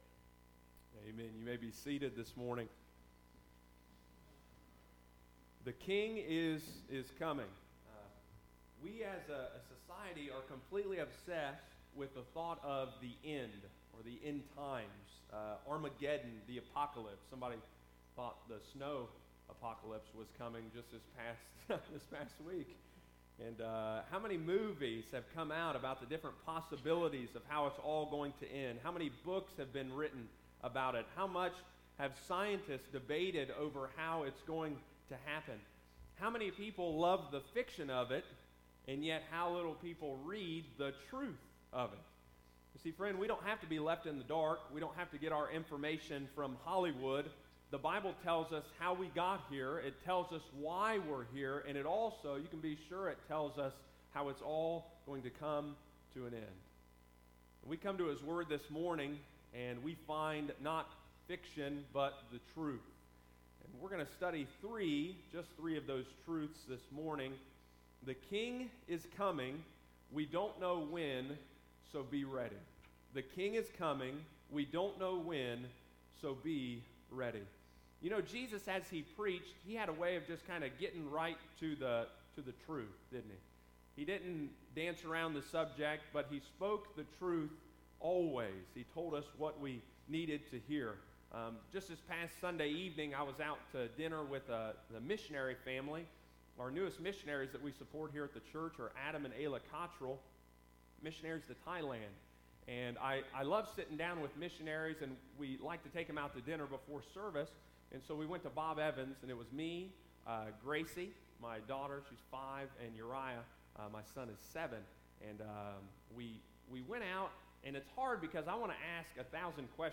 continues the Gospel of Luke series on Sunday morning, March 18, 2018.